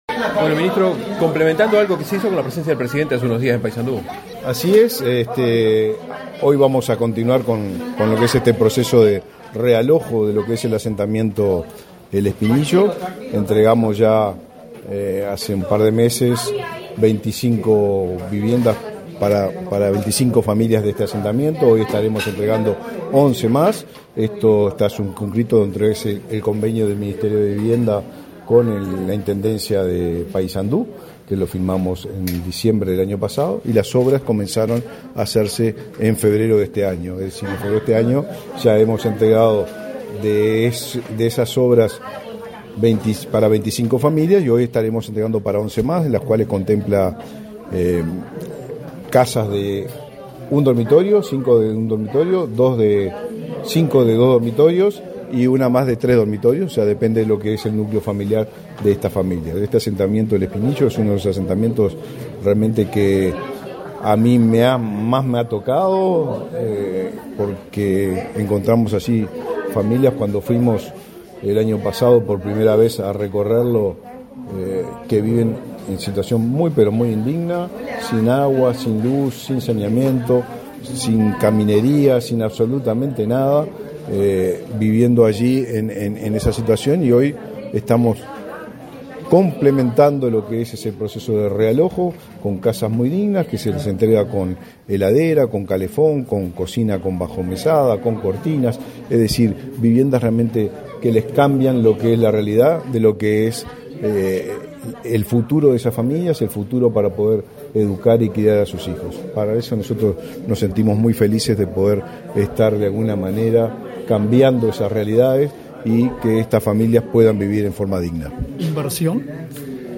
Declaraciones a la prensa del ministro de Vivienda y Ordenamiento Territorial, Raúl Lozano
Tras el evento, realizó declaraciones a la prensa.